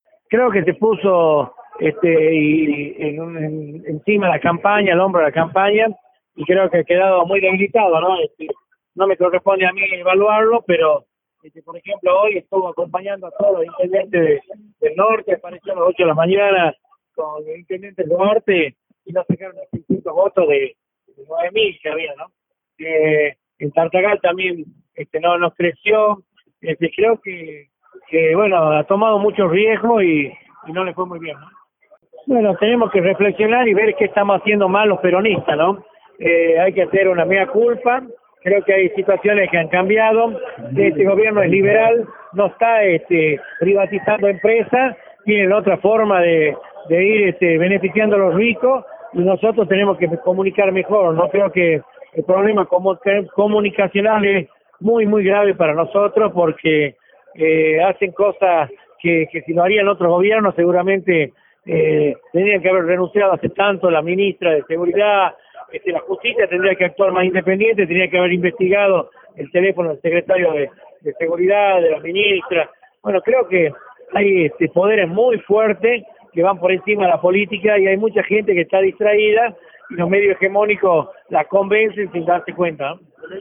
En diálogo con Radio Dinamo manifestó que el Gobernador se puso la campaña al hombro y por eso quedó debilitado.